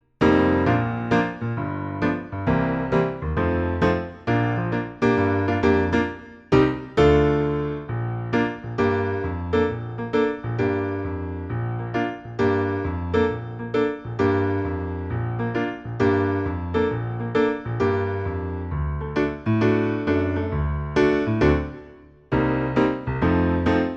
No Vocals At All Soundtracks 2:25 Buy £1.50